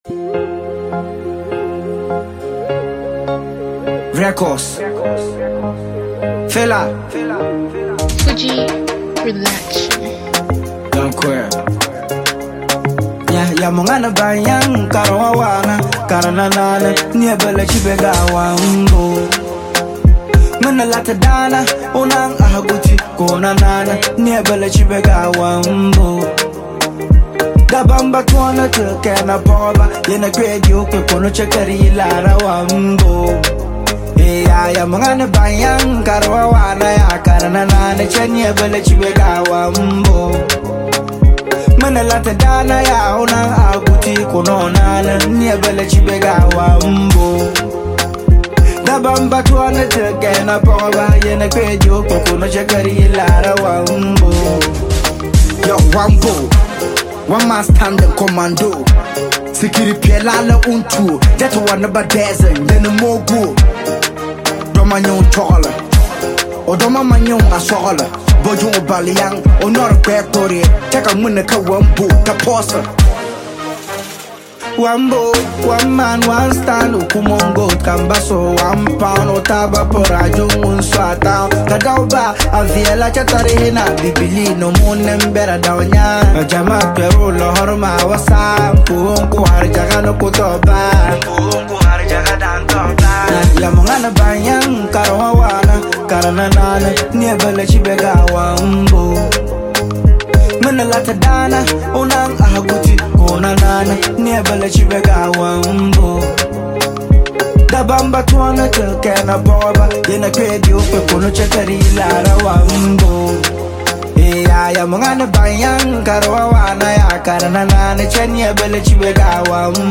a high-rated Northern-based rapper and songwriter